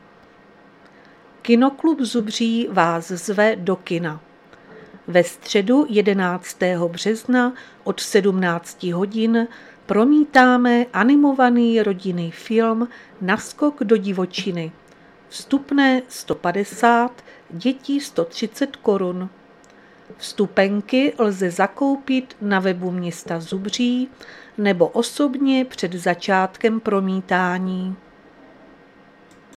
Záznam hlášení místního rozhlasu 10.3.2026